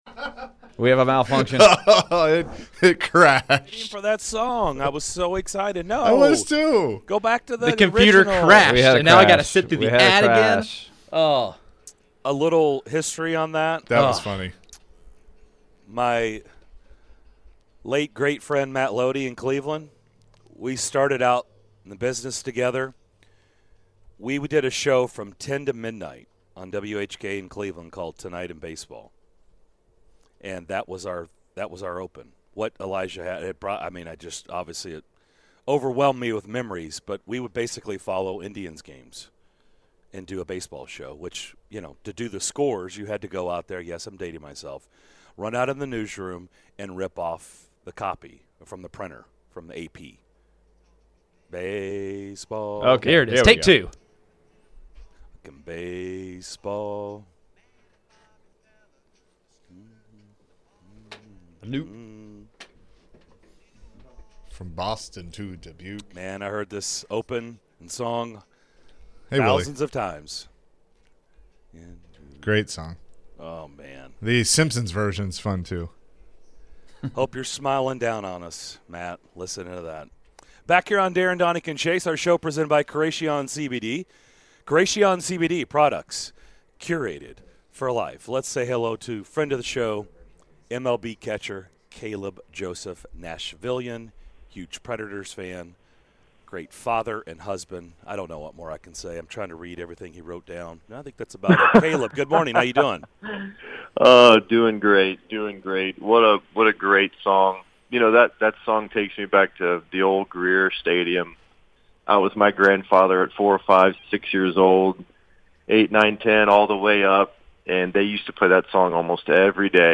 MLB Catcher Caleb Joseph joined the DDC to give his thoughts on the ALCS and the NLCS!